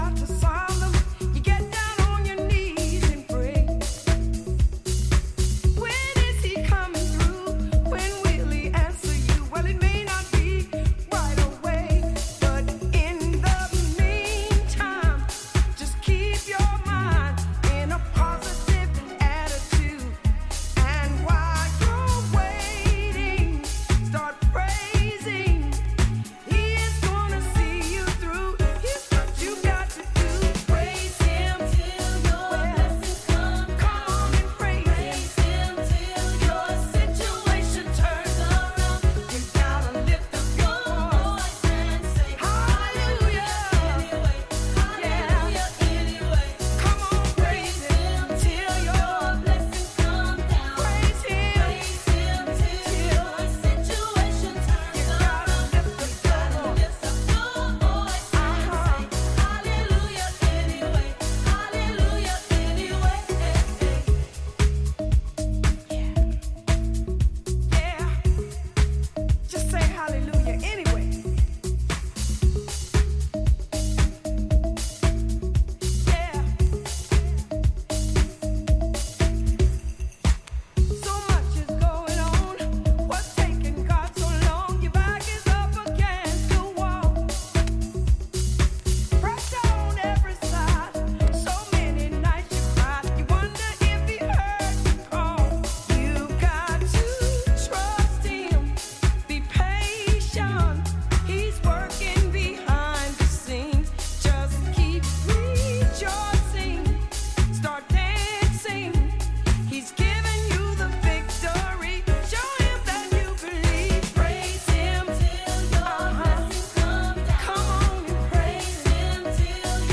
今後、定番的に楽しめるだろうオーセンティックなソウル感溢れる歌物ハウス集です！
ジャンル(スタイル) HOUSE / SOULFUL HOUSE